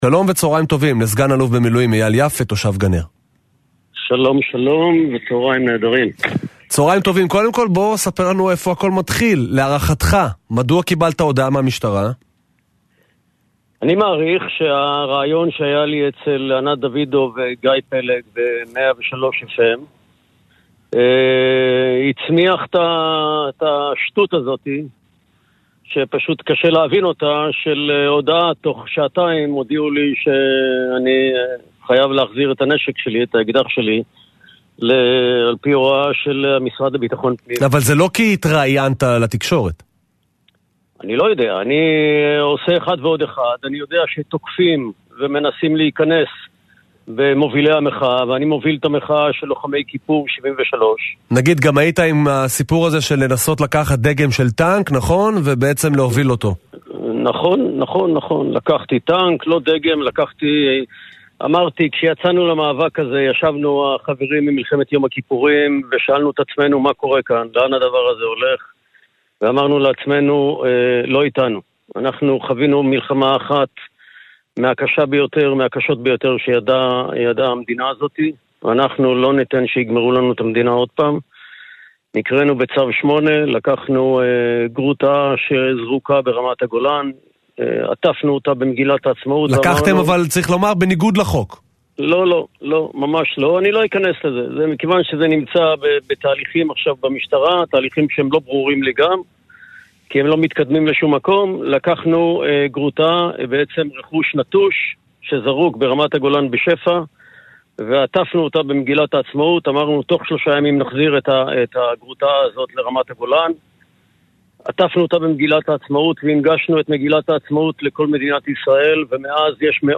מדברים ברדיו